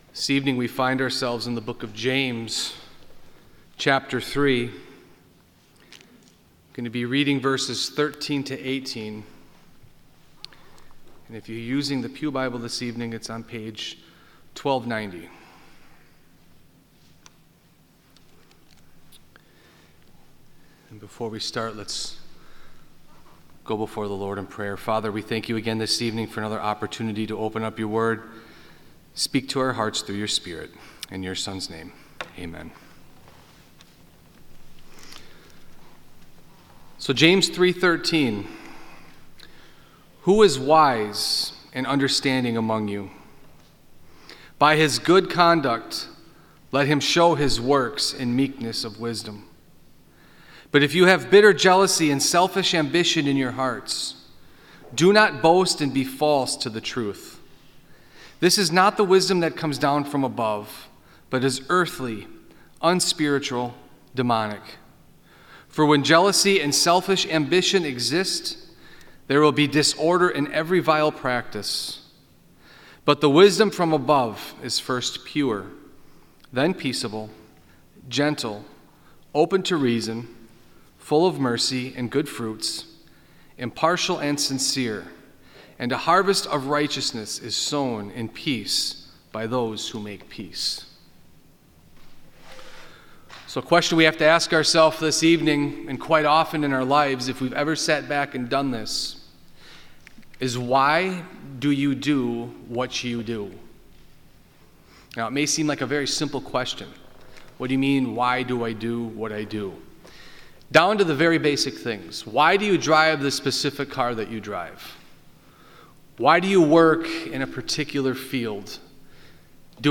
Various Sermons